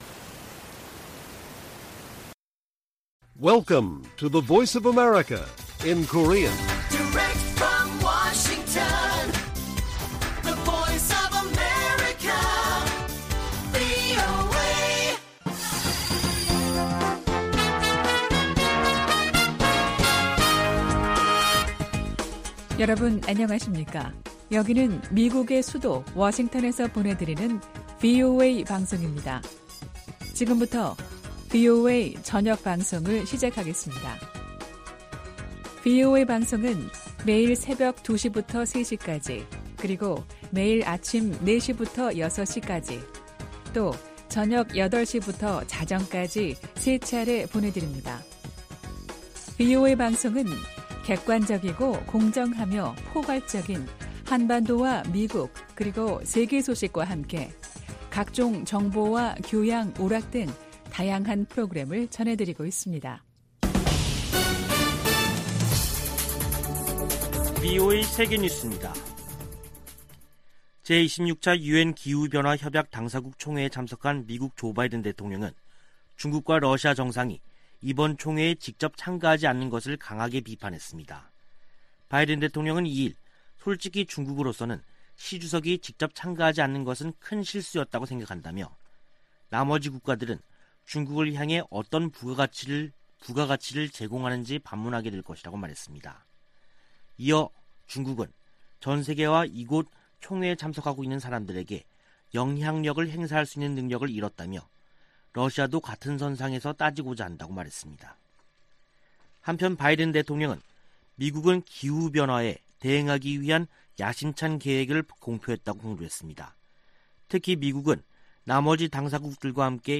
VOA 한국어 간판 뉴스 프로그램 '뉴스 투데이', 2021년 11월 3일 1부 방송입니다. 미 연방법원이 중국 기업과 관계자들의 대북제재 위반 자금에 대해 몰수 판결을 내렸습니다. 중국과 러시아가 유엔 안보리에 다시 대북제재 완화 결의안을 제출한 것은 미국과 한국의 틈을 벌리기 위한 것이라고 미국 전문가들이 분석했습니다.